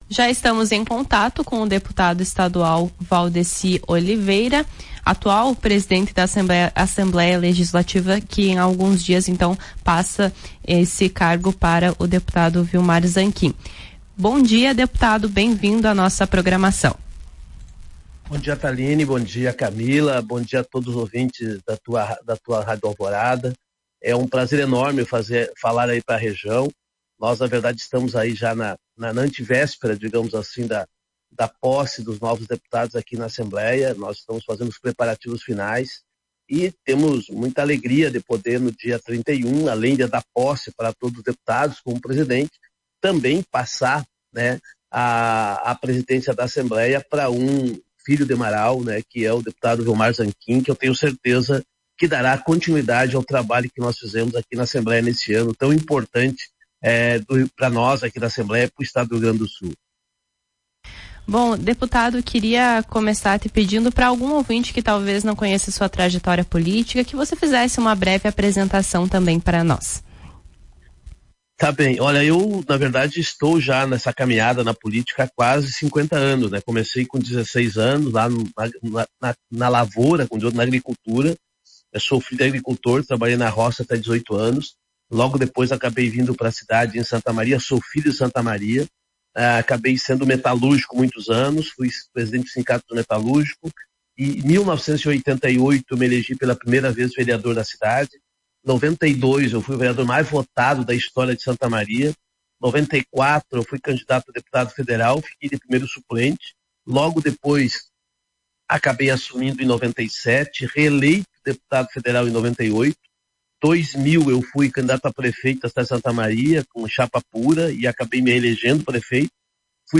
Em entrevista para a Tua Rádio Alvorada, Valdeci Oliveira, avaliou o trabalho realizado à frente da Assembleia em 2022, enfatizando que, apesar das tensões de um ano eleitoral, foi possível o diálogo com os diferentes partidos e foi respeitado o espaço dos parlamentares na casa. Ouça a entrevista de Valdeci na íntegra, no ícone de áudio.